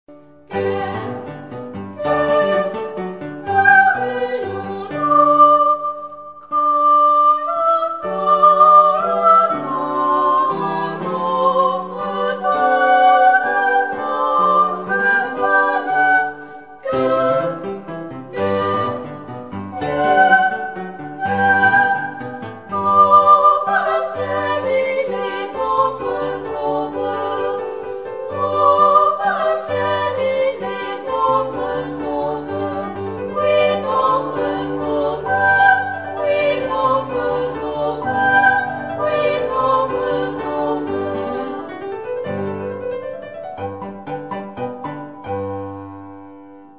Bastien Bastienne  Opéra pour Enfants de W.A. Mozart
Deux Concerts : Espace ONYX Centre Culturel de Saint-Herblain   Mars 1991